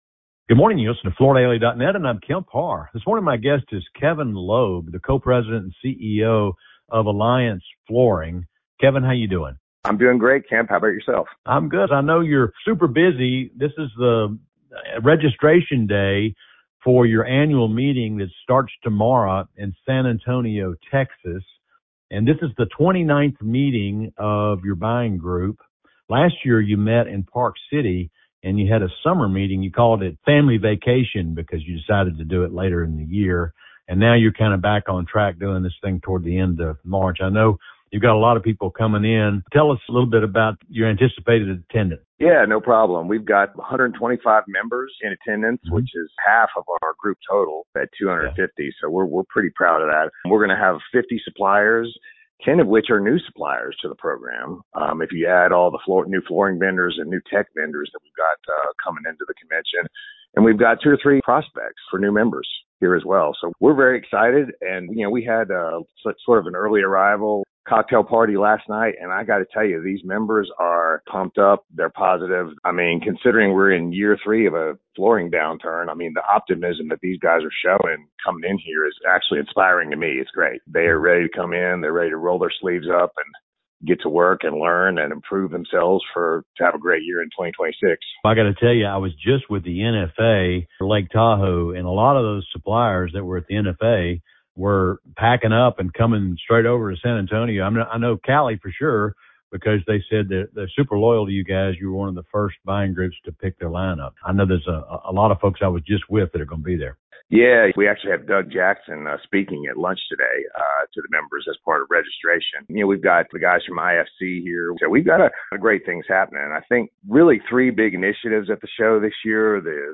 Listen to the interview to hear about Interface's global results, new personnel, focus on lean manufacturing plus an update on the FLOR business.